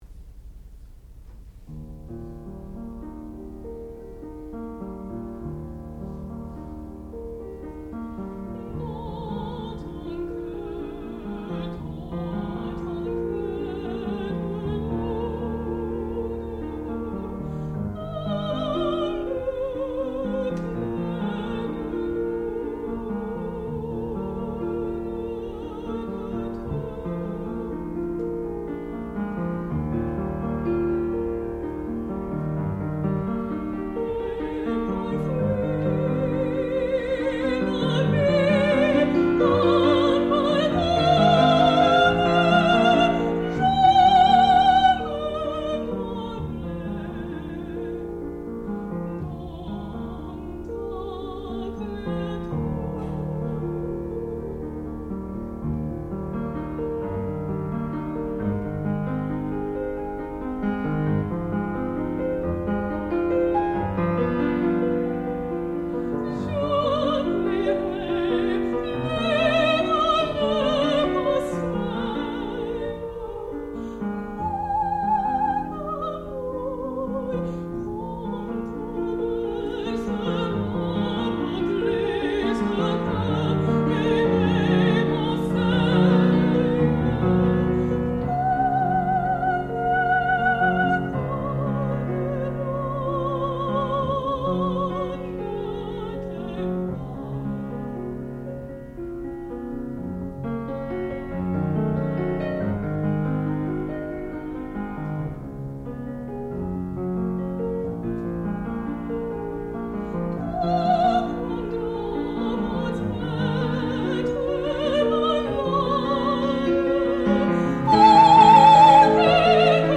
sound recording-musical
classical music
Qualifying Recital